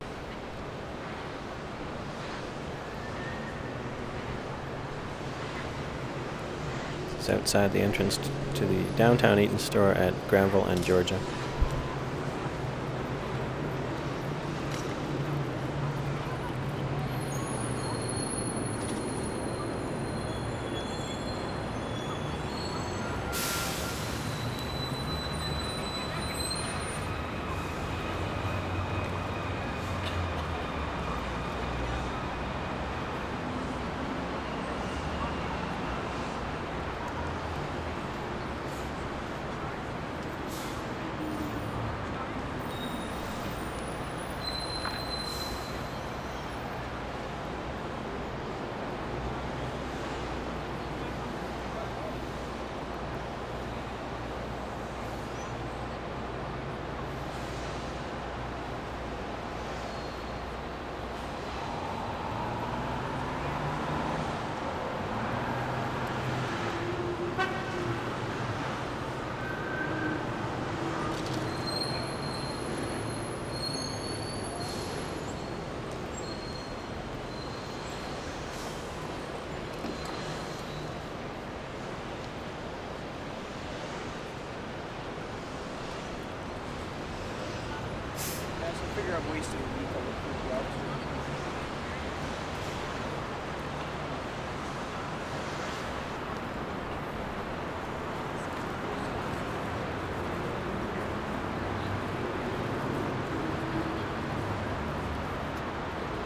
Granville and Georgia 1:49
16. ID, bus pass, brakes, gulls, conversation, horn at 1:09, overhead wires, air brakes at 0:24